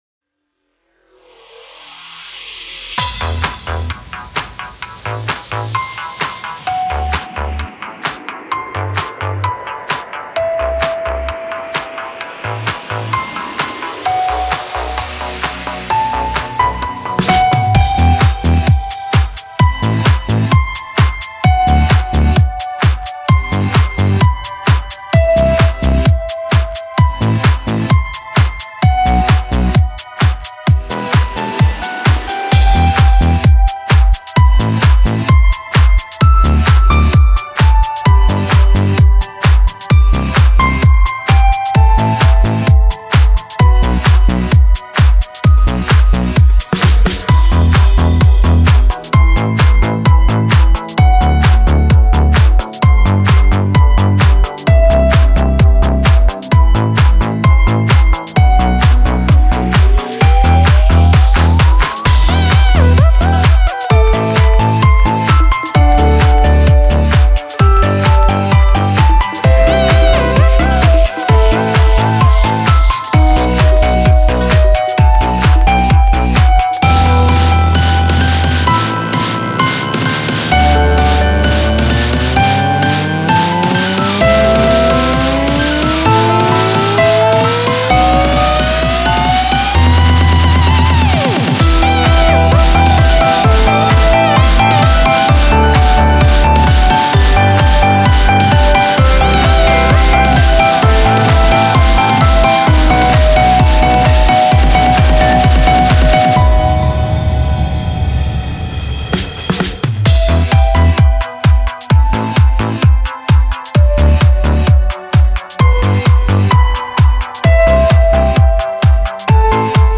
electronic
electro
instrumental
expressive
epic
ритмичная